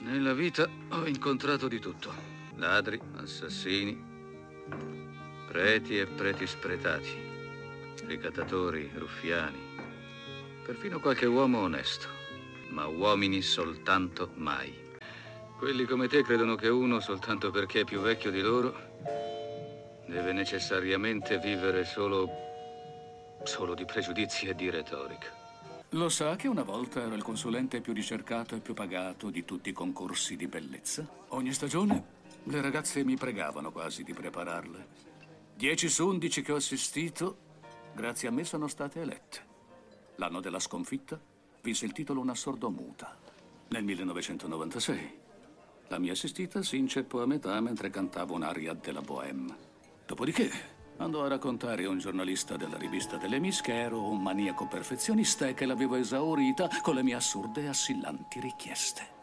voce di Nando Gazzolo nei film "Il mio nome � Nessuno", in cui doppia Henry Fonda, e "Miss Detective", in cui doppia Michael Caine.